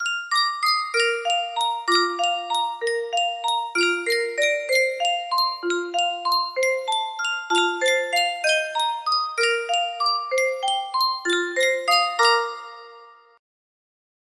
Sankyo Music Box - Down in the Valley KDF music box melody
Full range 60